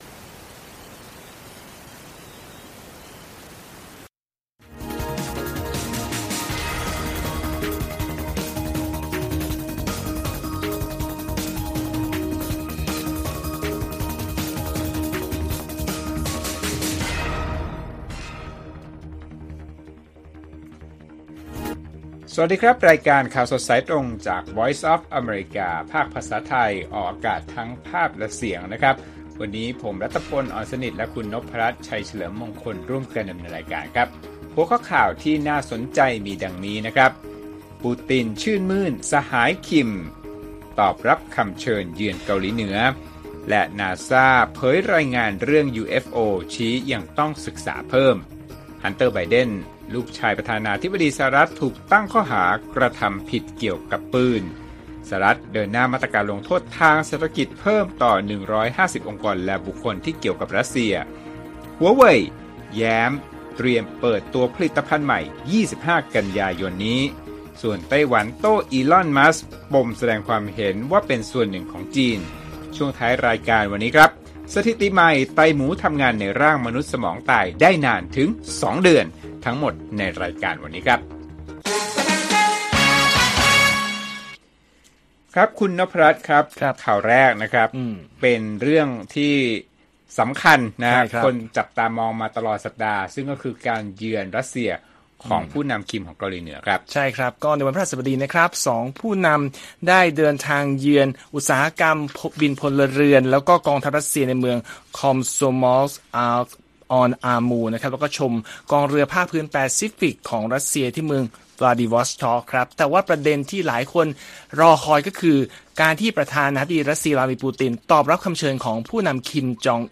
ข่าวสดสายตรงจากวีโอเอไทย 6:30 – 7:00 น. วันที่ 15 ก.ย. 2566